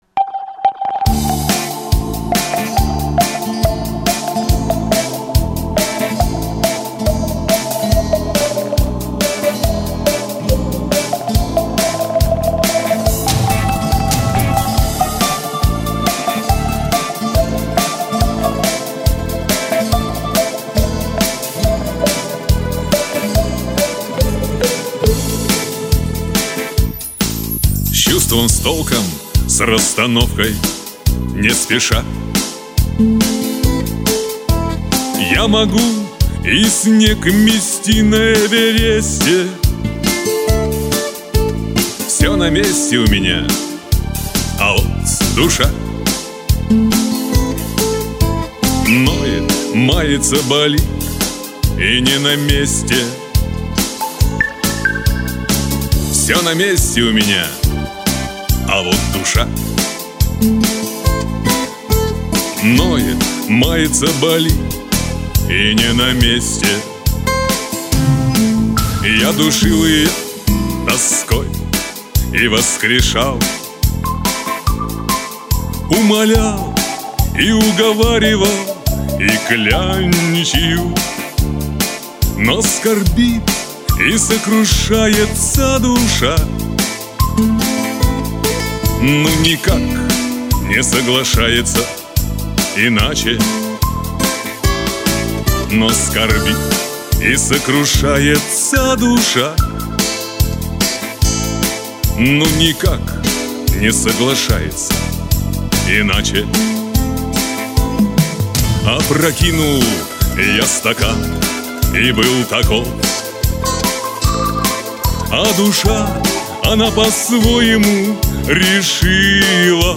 душевная-лирическая........